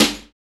Index of /90_sSampleCDs/Roland L-CDX-01/SNR_Snares 7/SNR_Sn Modules 7